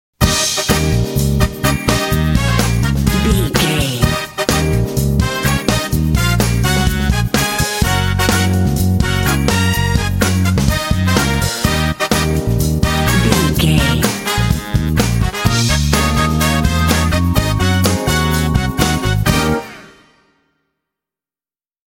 This 12-bar blues track
Aeolian/Minor
E♭
funky
happy
bouncy
groovy
bass guitar
drums
electric guitar
electric organ
brass
electric piano
jazz
blues